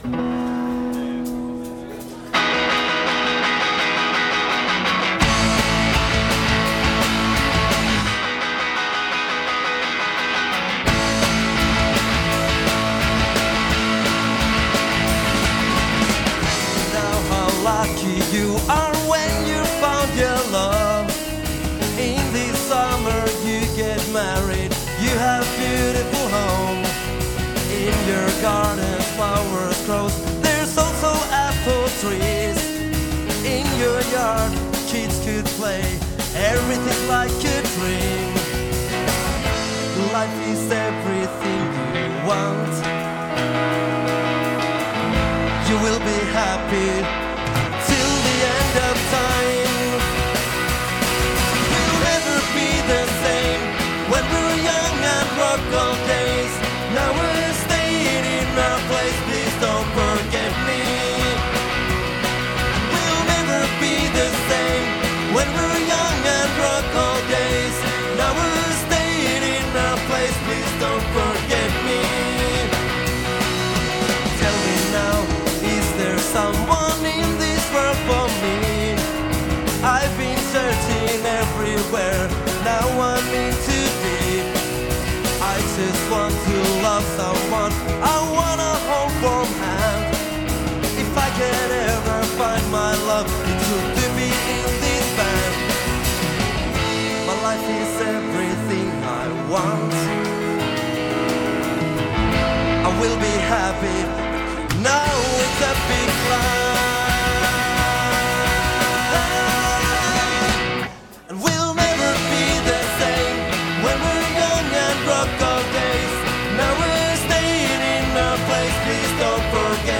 Turun Klubin keikka oli ja meni hyvin.